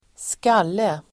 Uttal: [²sk'al:e]